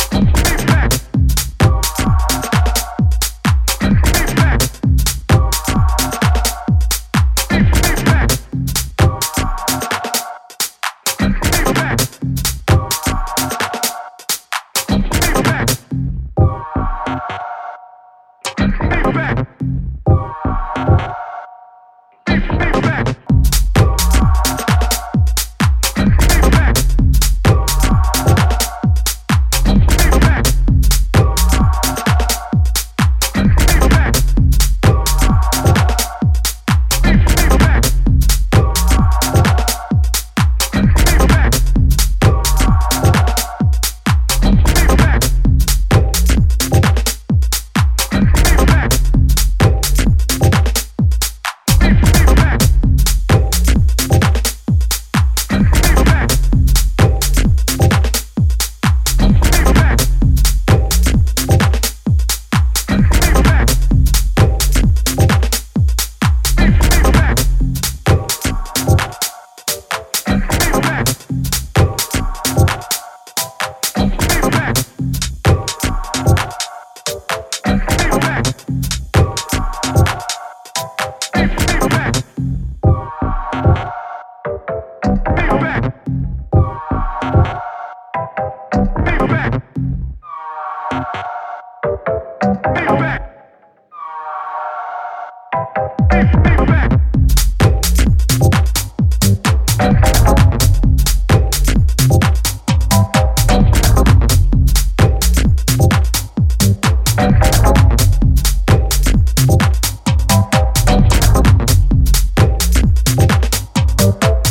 is tight and clipped in its tech funk